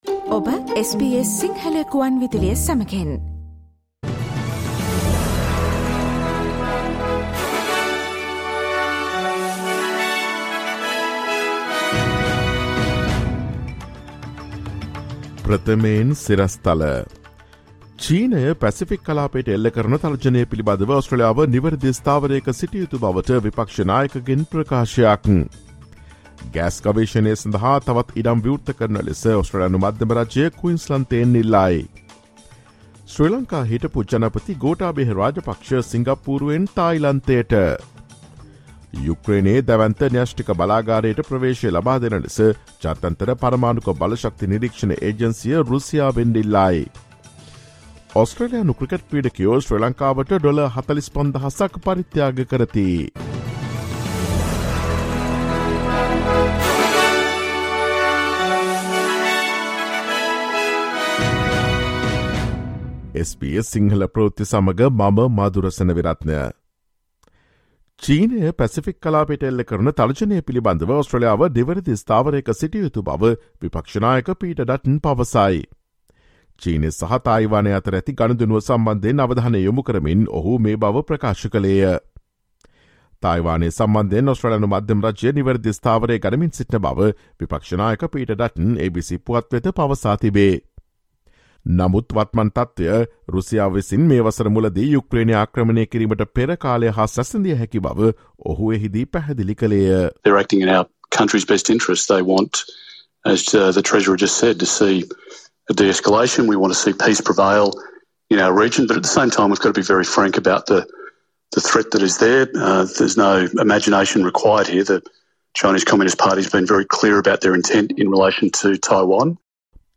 Listen to the latest news from Australia, Sri Lanka, across the globe, and the latest news from the sports world on SBS Sinhala radio news – Friday, 12 August 2022.